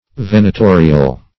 Search Result for " venatorial" : The Collaborative International Dictionary of English v.0.48: Venatorial \Ven`a*to"ri*al\, a. [L. venatorius.] Or or pertaining to hunting; venatic.